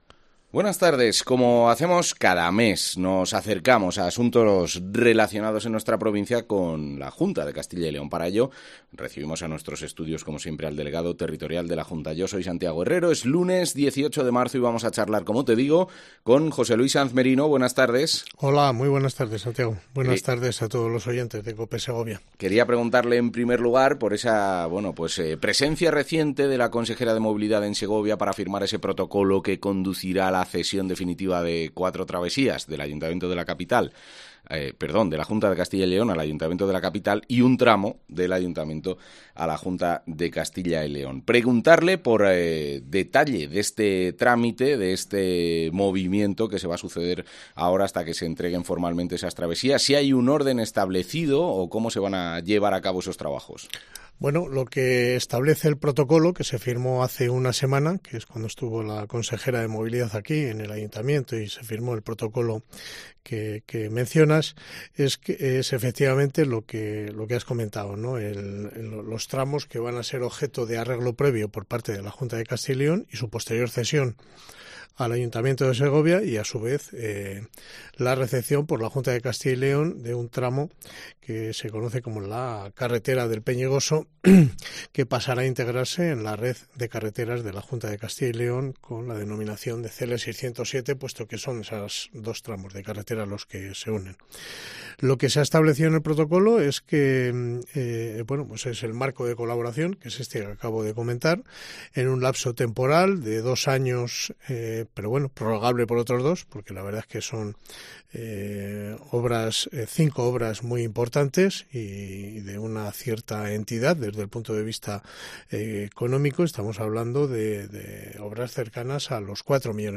José Luis Sanz Merino, delegado territorial de la Junta de Castilla y León en Segovia
Labores que ya han empezado, según ha indicado, en Mediodía COPE en Segovia, el delegado territorial de la Junta, José Luis Sanz Merino.